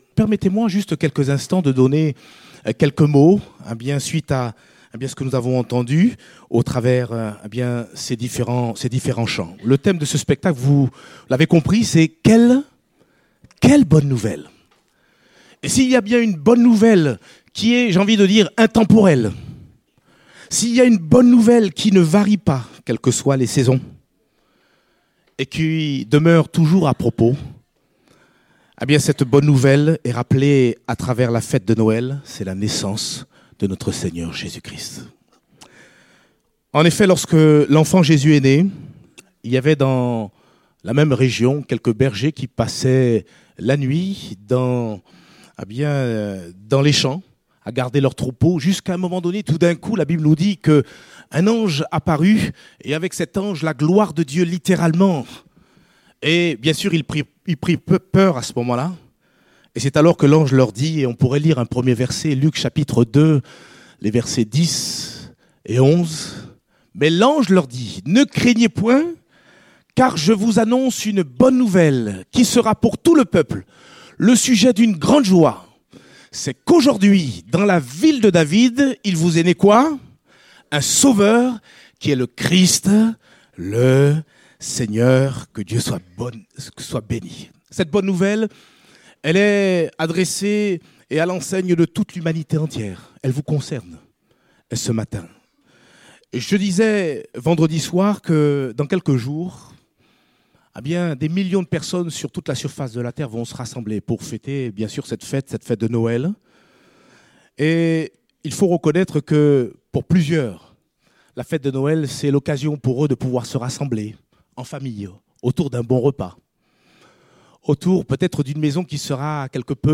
Date : 17 décembre 2017 (Culte Dominical)